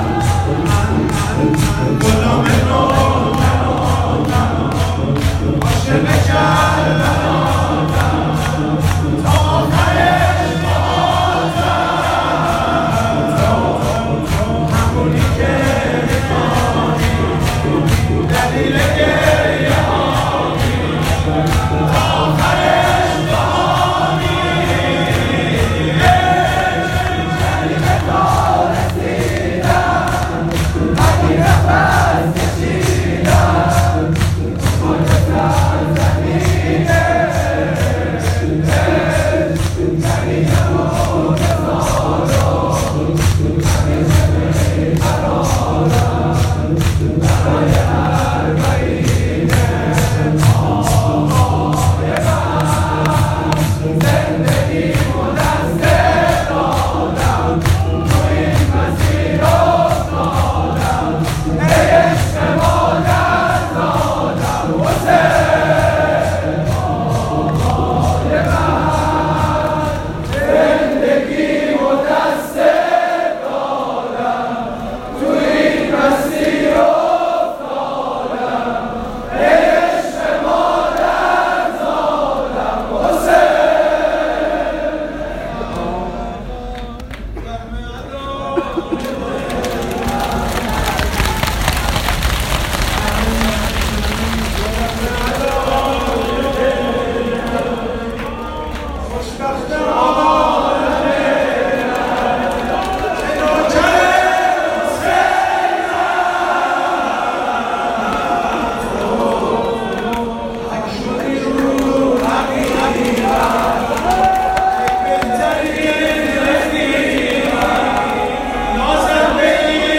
همخونی بسیار زیبا
هیئت انصار الحجه مشهد مقدس ---------------------------------------------- ببخشید به خاطر کیفیت نامطلوب ترک چون ضبط خودمه بد شده وگرنه صوت اصلی هروقت اومد اون رو جایگزین میکنم
مداحی